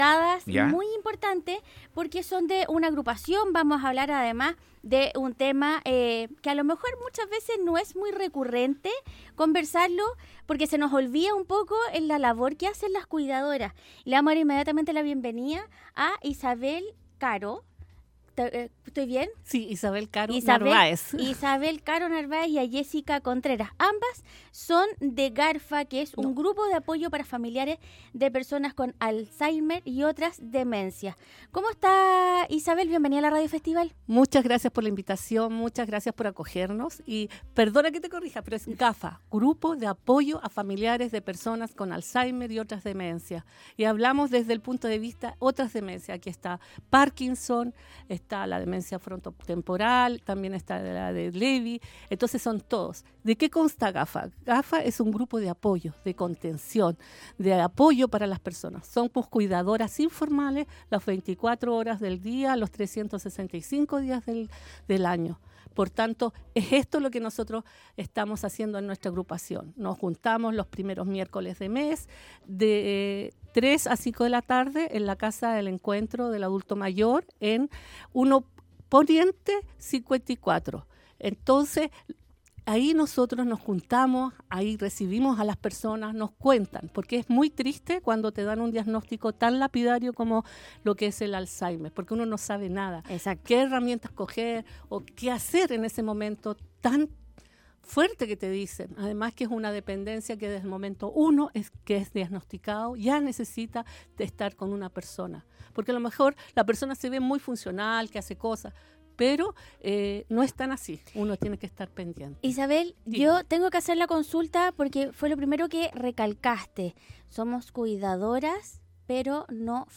Grupo de apoyo para Familias de personas con Alzheimer y otras demencias GAFA Chile, estuvo en los estudios contando detalles de las cuidadoras.